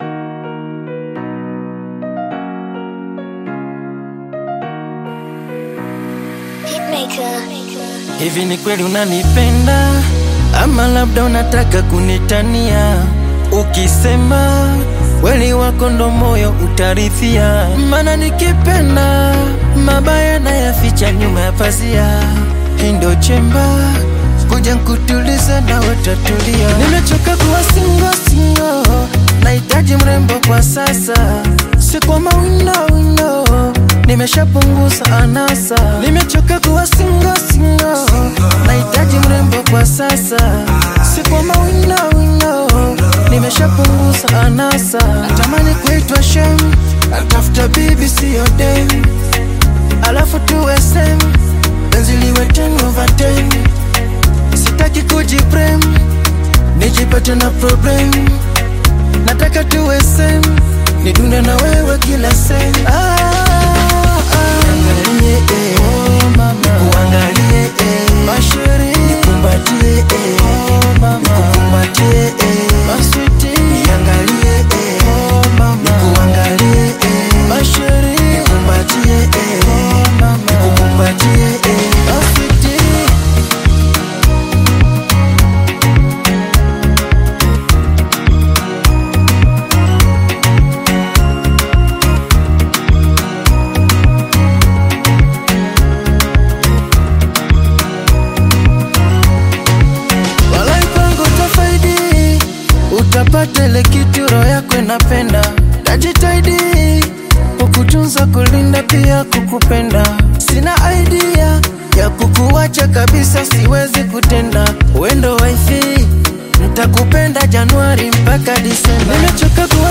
smooth vocals
afrobeat-infused electronic music